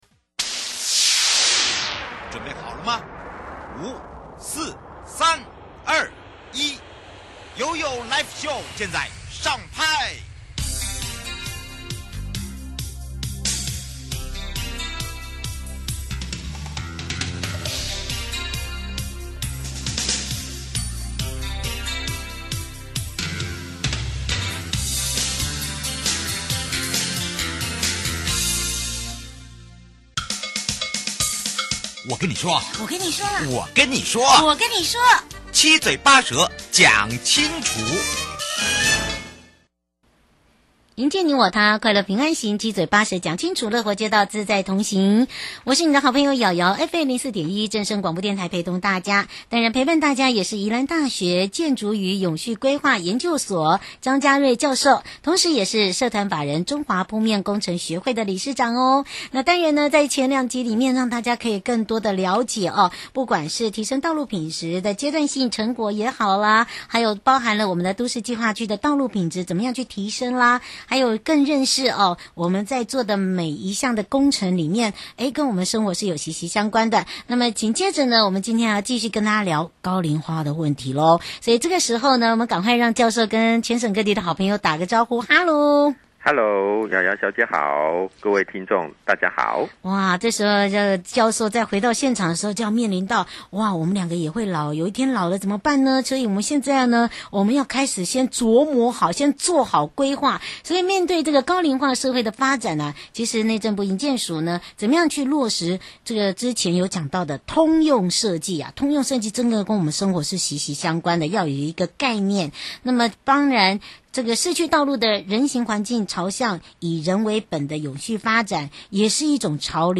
受訪者： 營建你我他-快樂平安行-七嘴八舌講清楚- 面對高齡化社會的發展,內政部營建署如何落實通用設計理念,將市區道路人行環境朝向以人為本永續發展的潮流來推動?社團法人中華鋪面工程學會持續協助內政部營建署辦理全國的(市區道路養護暨人行環境無障礙考評計畫,多年來的執行心得為何?